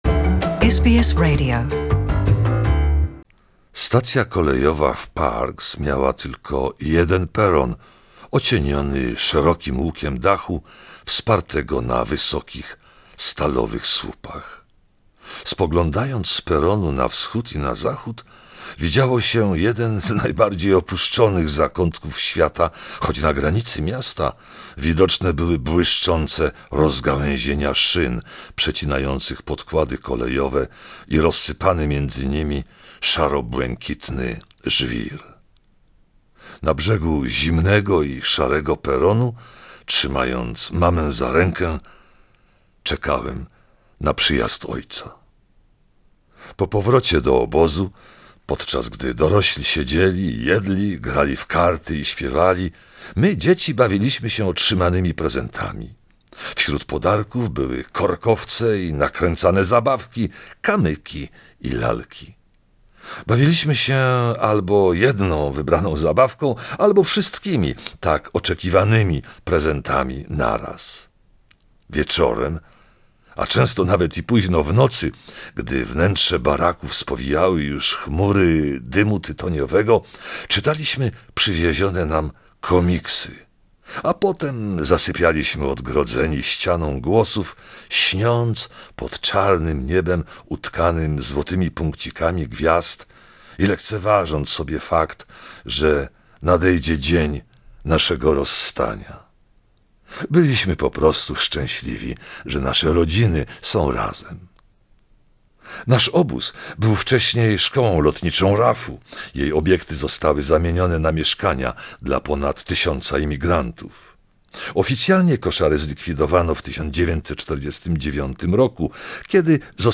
'The Sparrow Garden', the book by Australian author Peter Skrzynecki is a powerful memoir of the migrant child who travels from the Displaced Persons camps of Germany to the suburban of outer Sydney. 3 excerpts of the book